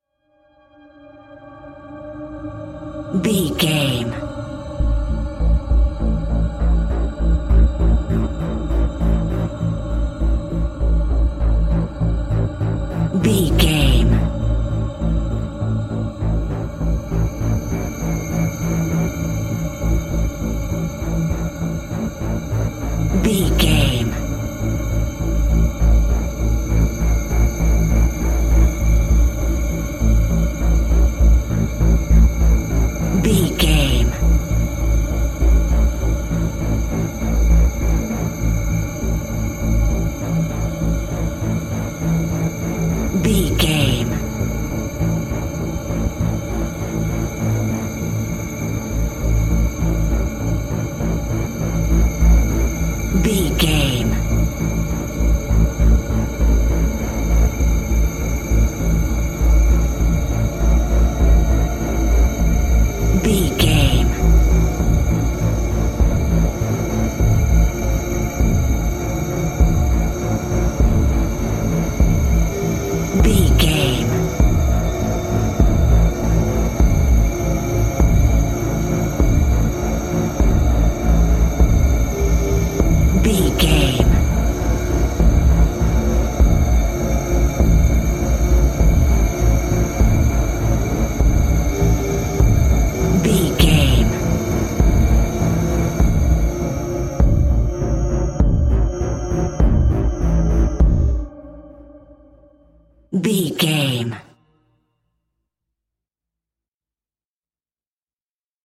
Thriller
Aeolian/Minor
synthesiser
percussion